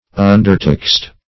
\Un"der*taxed`\